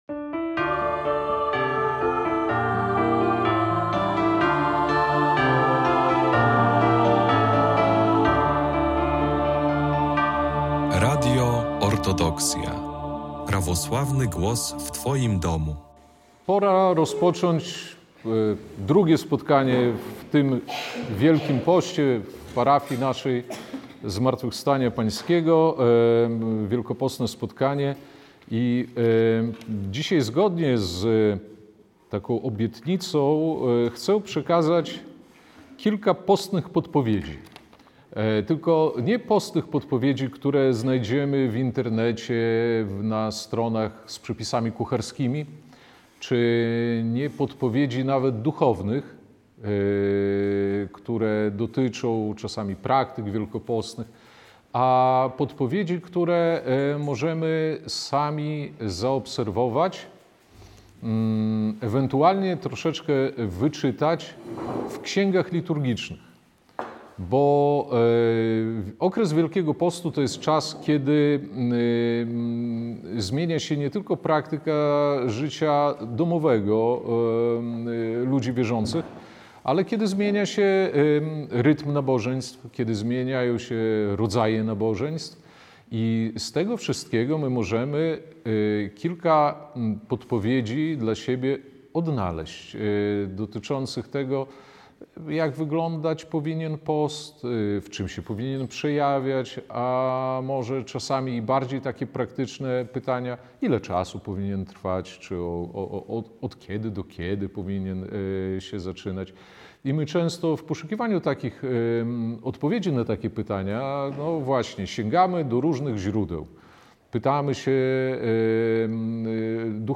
Postne podpowiedzi z triodionu – wykład